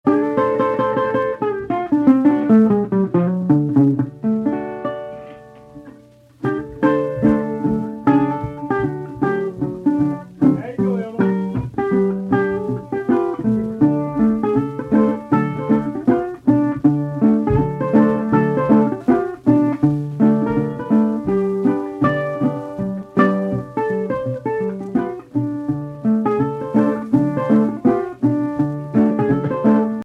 Voicing: Guitar Tab